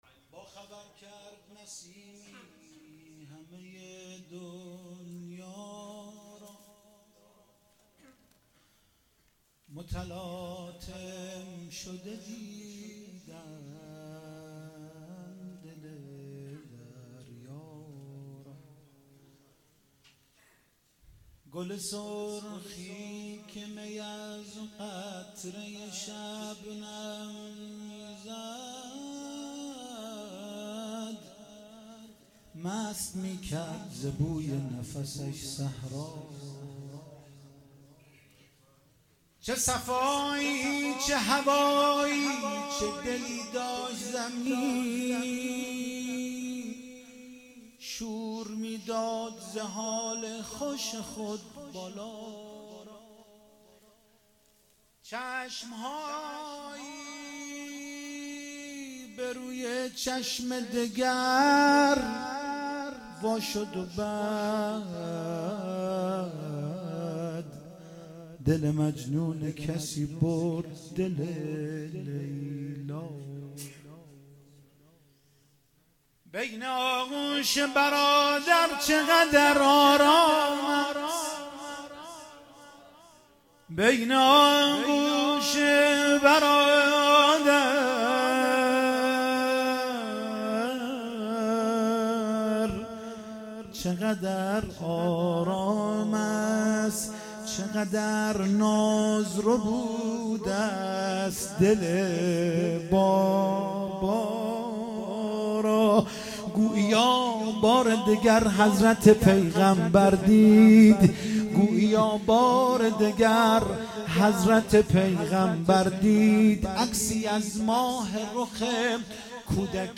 ولادت حضرت زينب (س) 95 - مدح